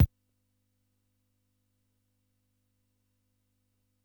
Index of /90_sSampleCDs/300 Drum Machines/Keytek MDP-40